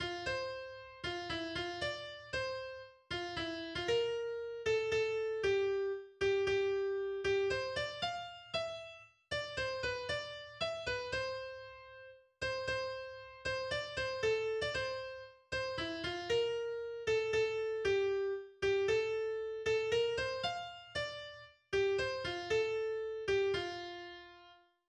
Weihnachtslied